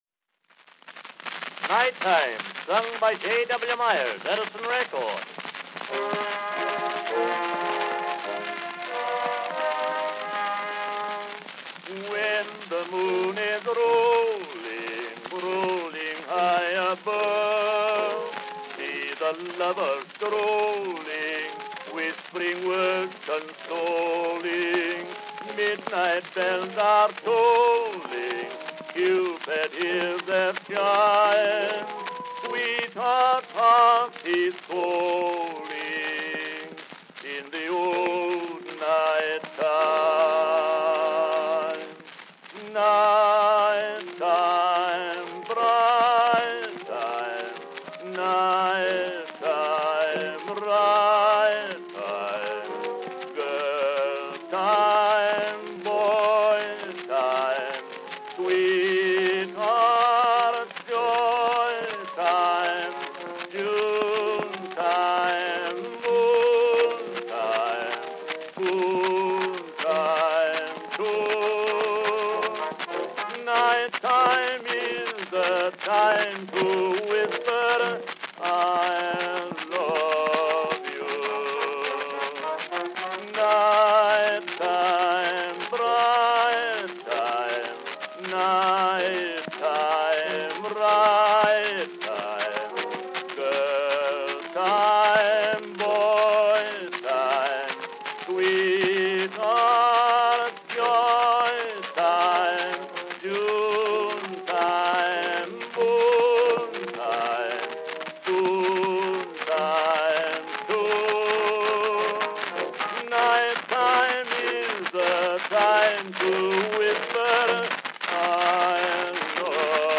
Baritone solo with orchestra accompaniment.
Popular music—1901-1910.
Musicals—Excerpts.